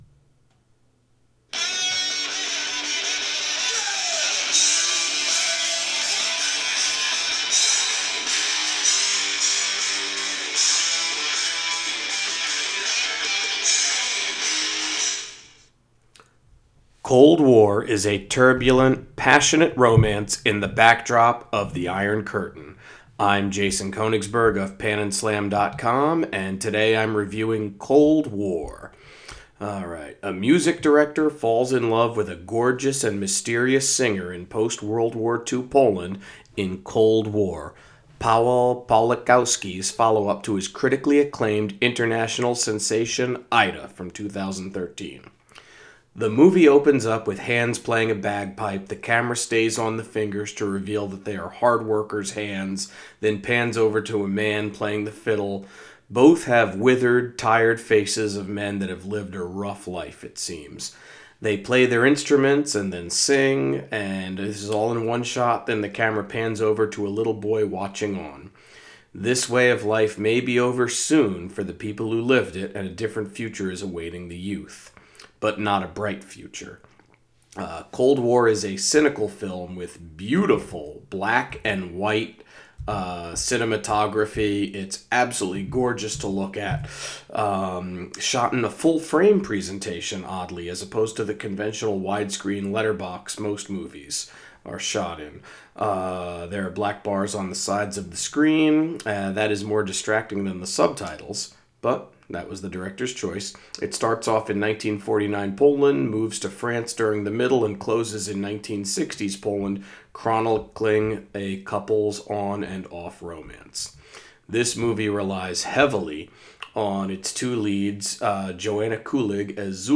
Movie Review: Cold War